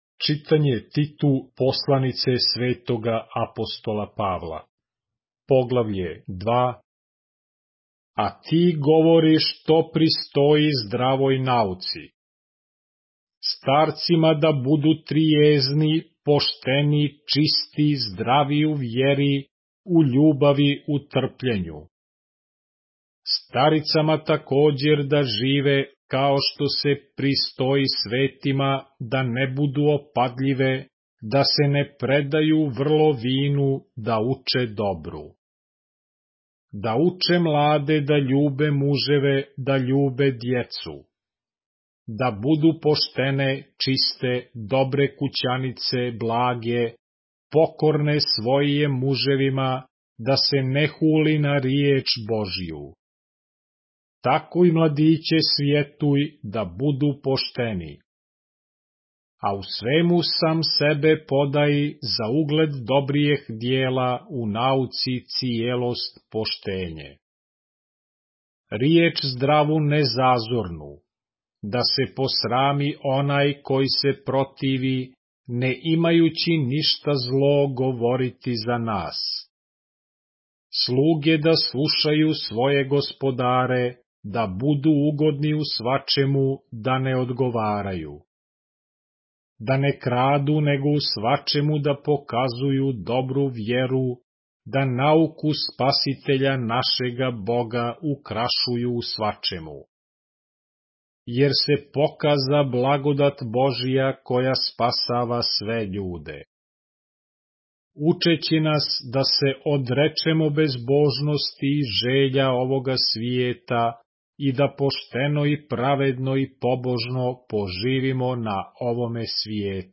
поглавље српске Библије - са аудио нарације - Titus, chapter 2 of the Holy Bible in the Serbian language